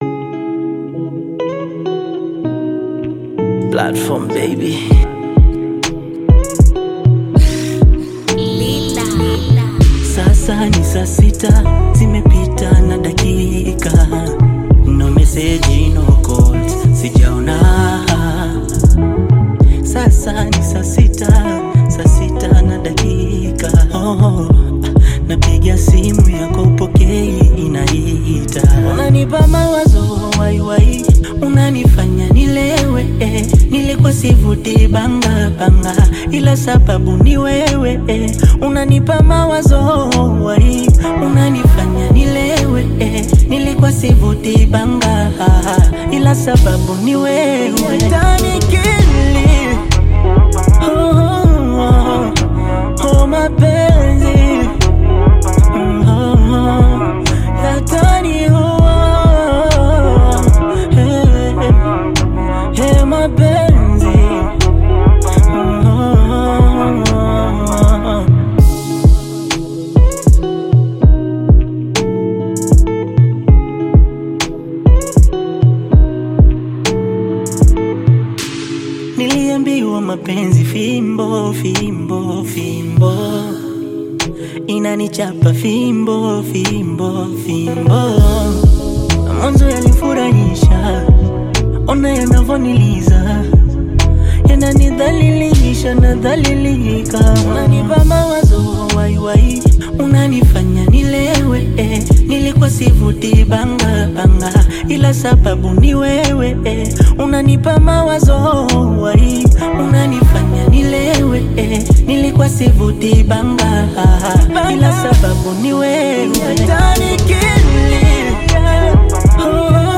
Tanzanian music group
fresh and energetic new track